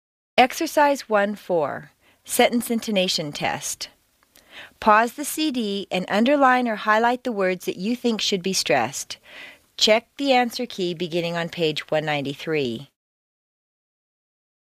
美式英语正音训练第8期:句子语调测试 听力文件下载—在线英语听力室